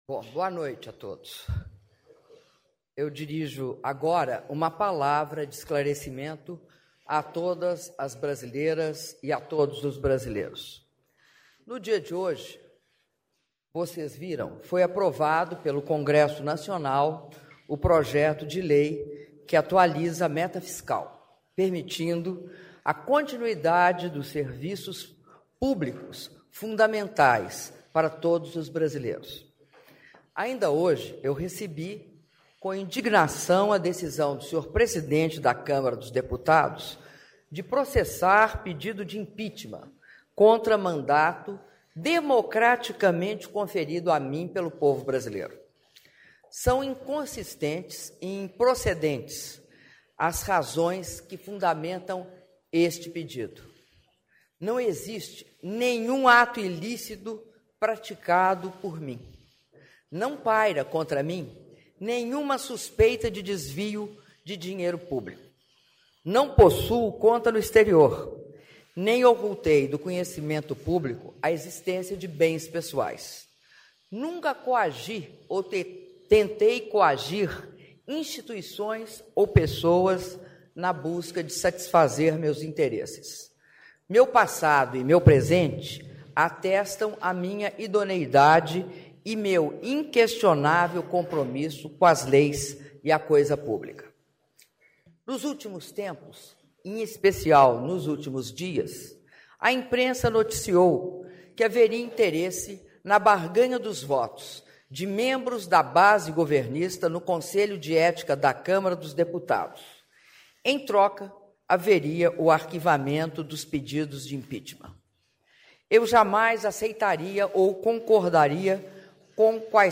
Áudio do pronunciamento à imprensa da Presidenta da República, Dilma Rousseff - Palácio do Planalto (02min55s)